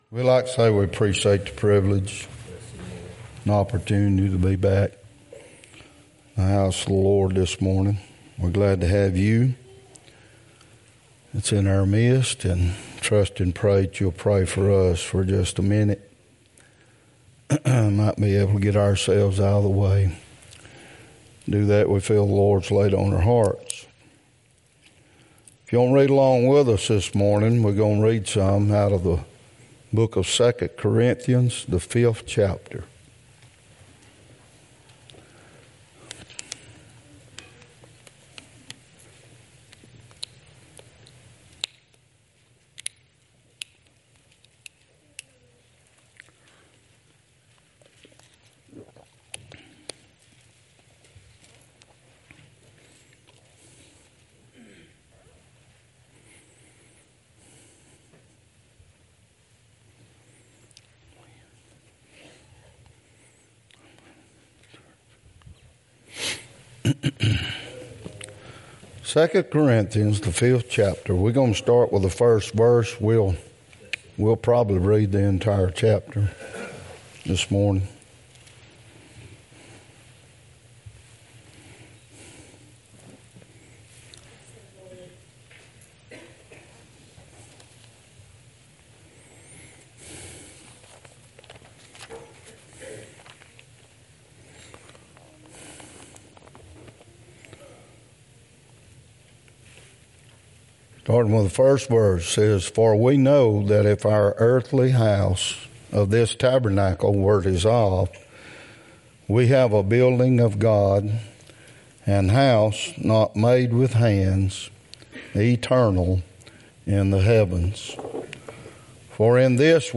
2025 Passage: 2 Corinthians 5:1-21 Service Type: Sunday Topics